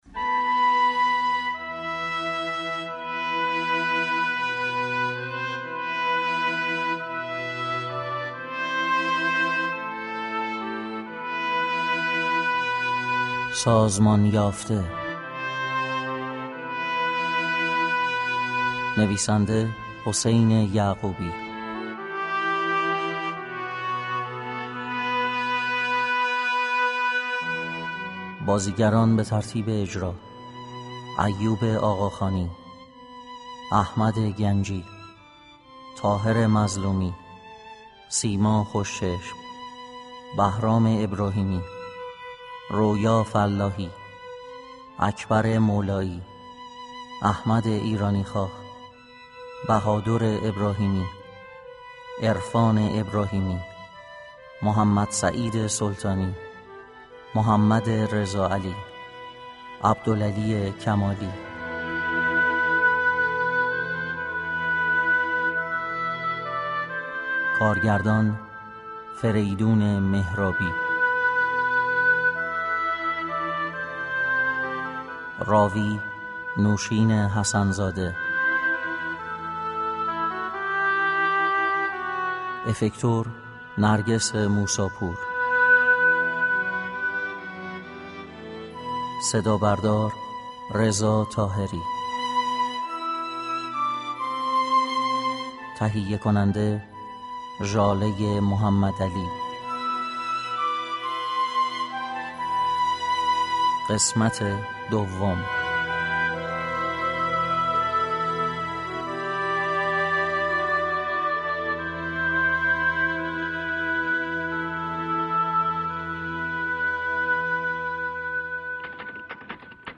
چهارشنبه سوم بهمن ماه ، شنونده‌ی نمایش رادیویی